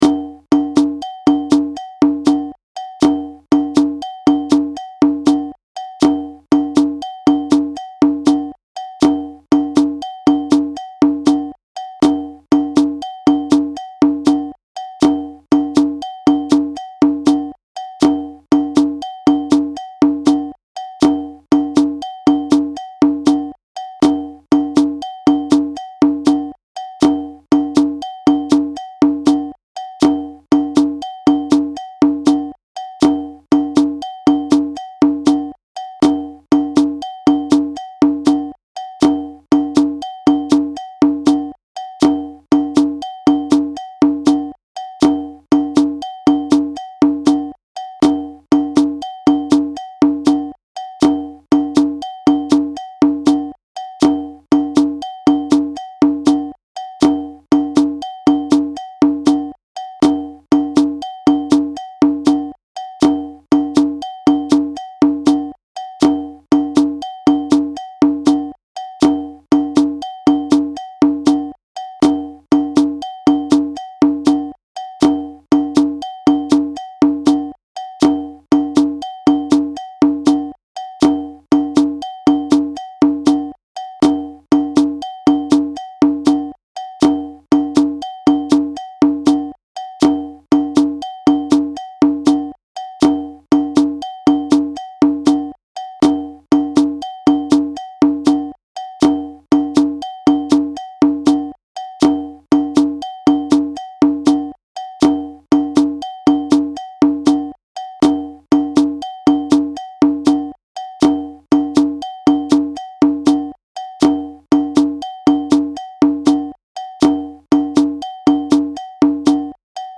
12/8 Djembe Break
A typical break phrase commonly used in djembe music to start or stop a rhythm or transition into another section.
audio (with shekeré  & bell)
Djembe-Break-12_8-hh.mp3